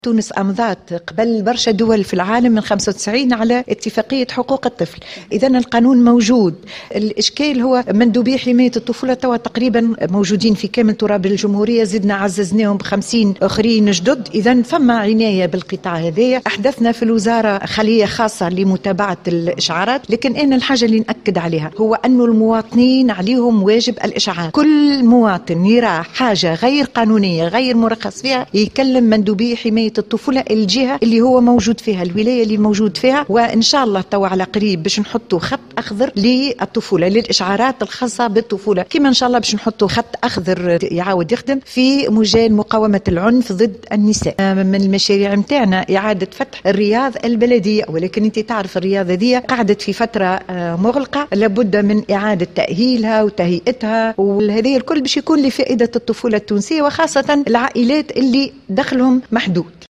أعلنت وزيرة المرأة والأسرة والطفولة نزيهة العبيدي على هامش مشاركتها اليوم الأحد 13 نوفمبر 2016 في الاحتفال الجهوي بالعيد الوطني للشجرة بنابل عن تعزيز سلك مندوبي حماية الطفولة وذلك بإضافة 50 مندوب حماية طفولة جديد.